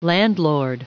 Prononciation du mot landlord en anglais (fichier audio)